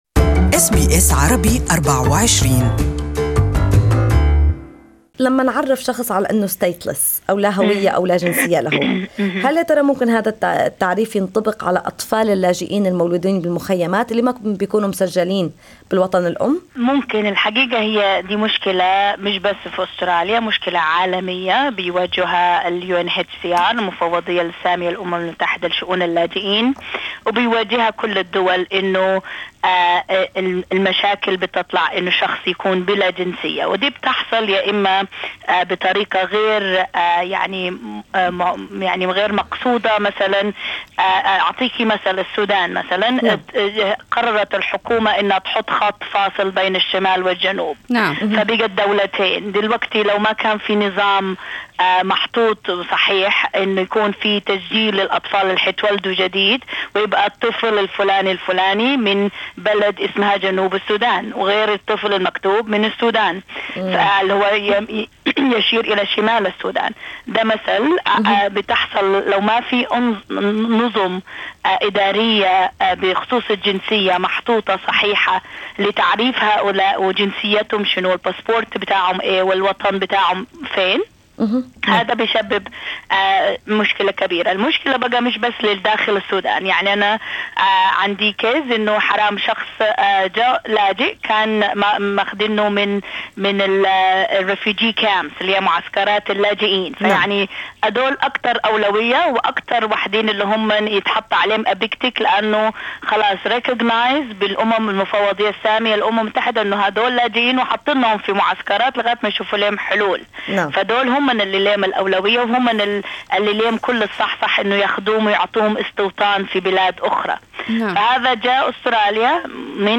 Al bayt Baytak interviewed Immigration lawyer